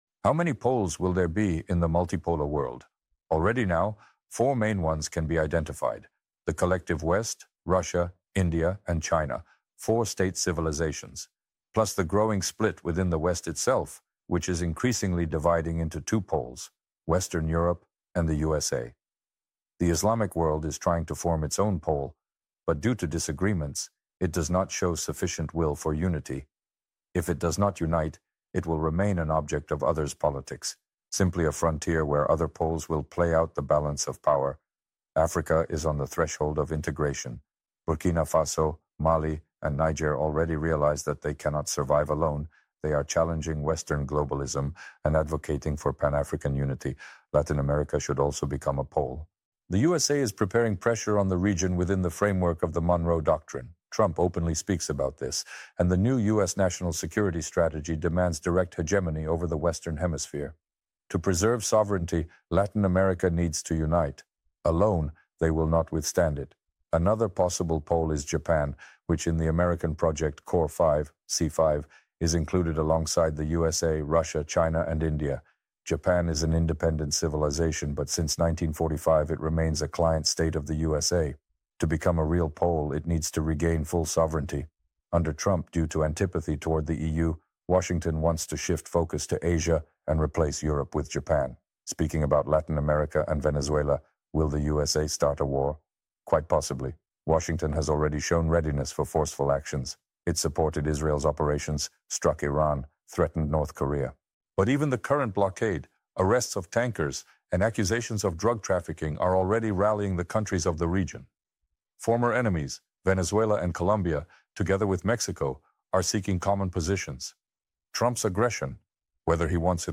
With 2026 on the horizon, AI Dugin breaks down tomorrow's geopolitics